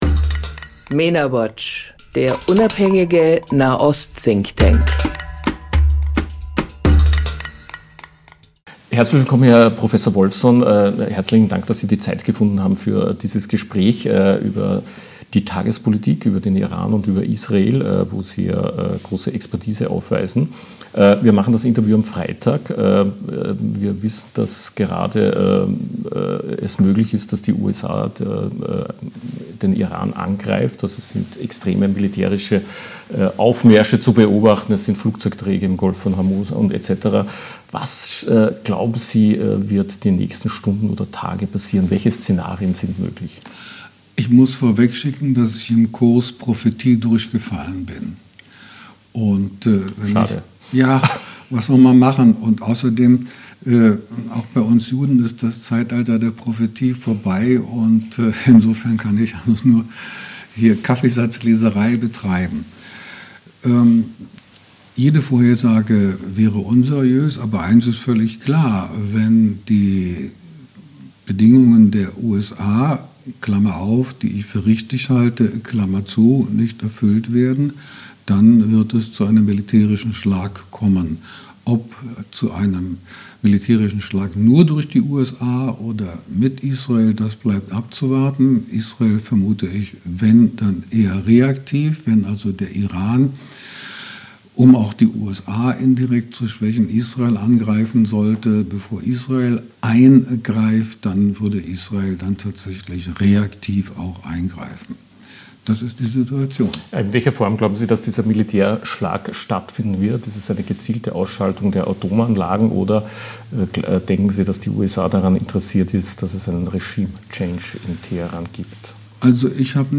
Im Mena-Watch-Interview analysiert der deutsche Historiker Michael Wolffsohn die möglichen Szenarien im Konflikt mit dem Iran sowie die politischen Perspektiven Israels.